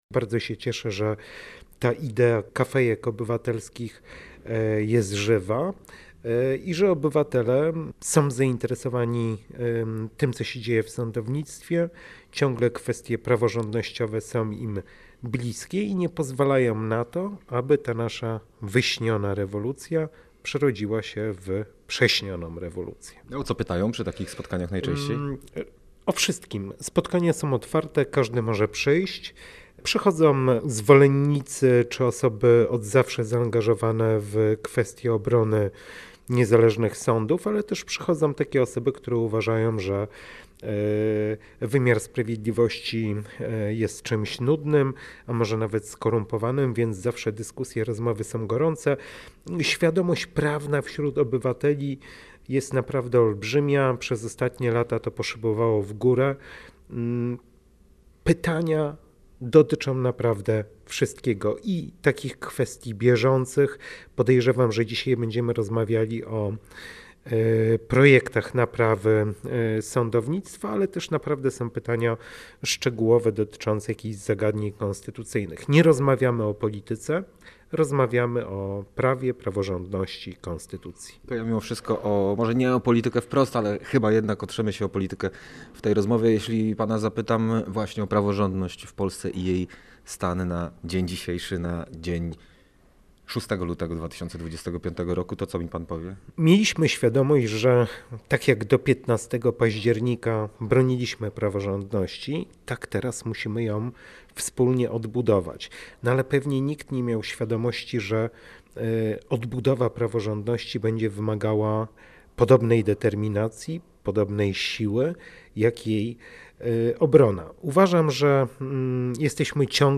Nawet jeśli zmienimy przepisy, odbudowa autorytetu instytucji związanych z praworządnością zajmie pokolenia – stwierdził w rozmowie z Radiem Gdańsk Igor Tuleya, sędzia Sądu Okręgowego w Warszawie reprezentujący stowarzyszenie Iustitia.